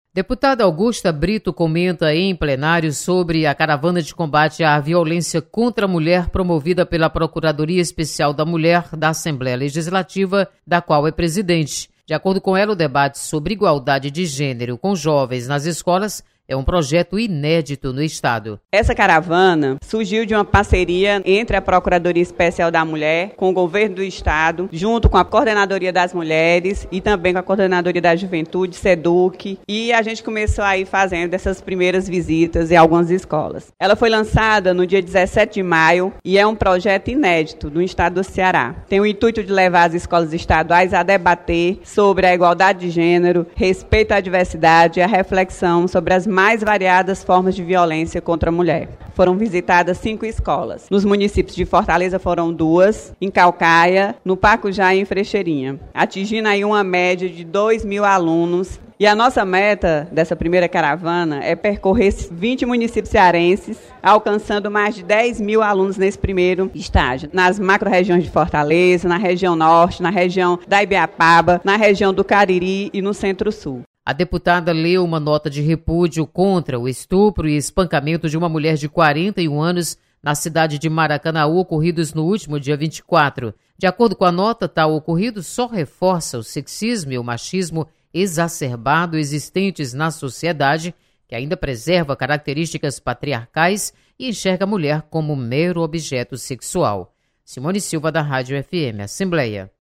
Caravana da Mulher é recebida por dois mil alunos. Repórter